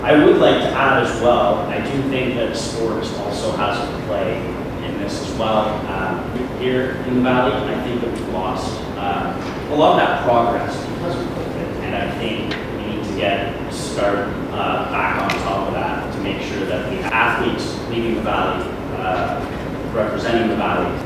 VALLEY VOTES: Algonquin-Renfrew-Pembroke federal candidates debate at Festival Hall L’Equinox a success